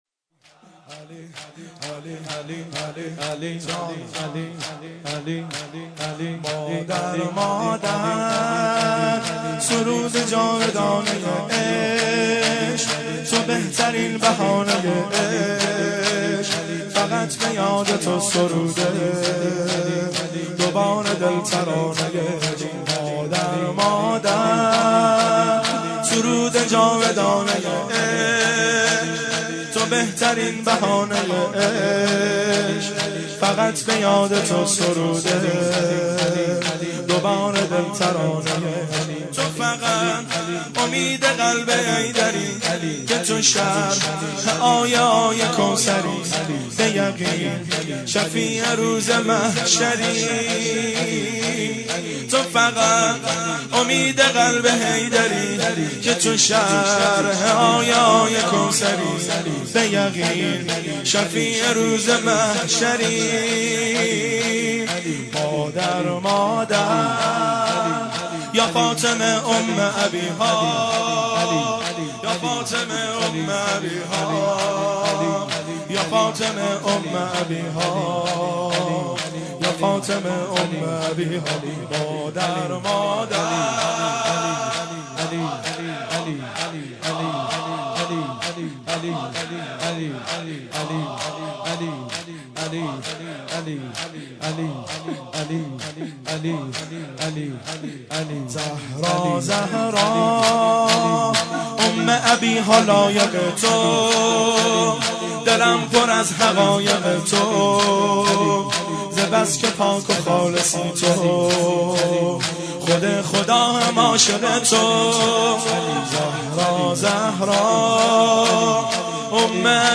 سینه زنی در شهادت بی بی دوعالم حضرت زهرا(س